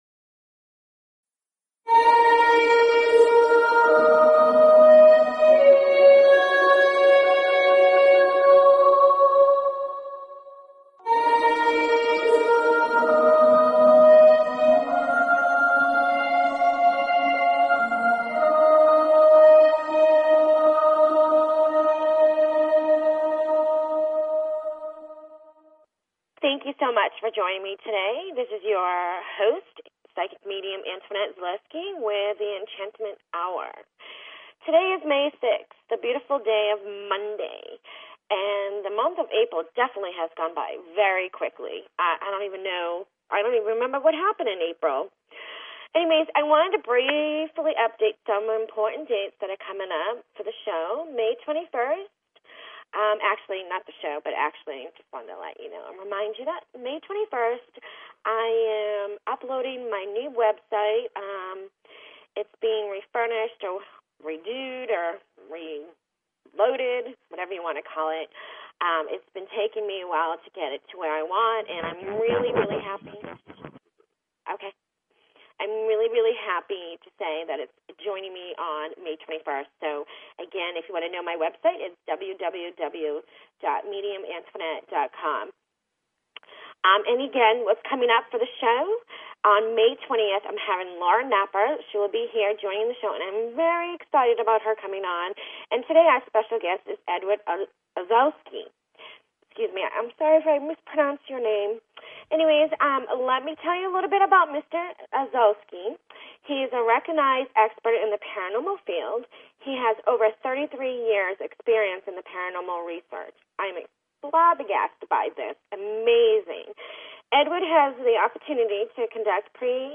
Talk Show Episode, Audio Podcast, Enlightenment_Hour and Courtesy of BBS Radio on , show guests , about , categorized as